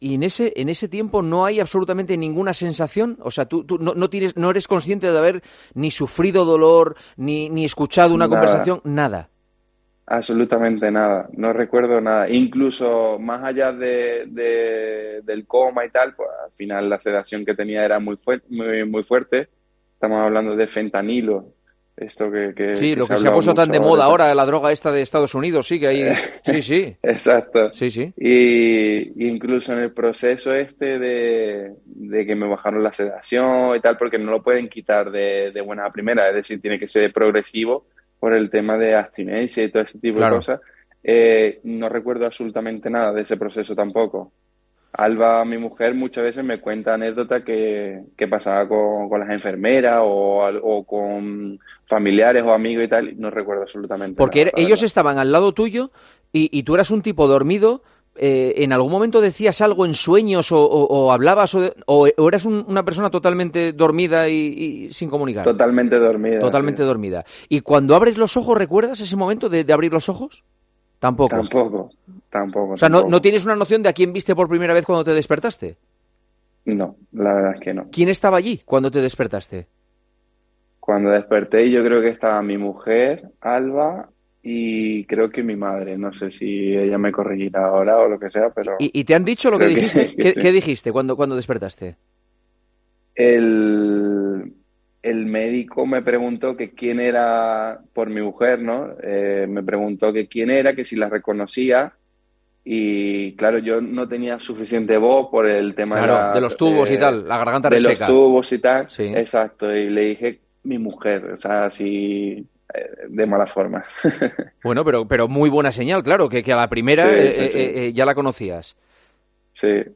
El portero del París Saint-Germain pasó este jueves por los micrófonos de El Partidazo de COPE junto a Juanma Castaño y contó como se recupera del grave accidente.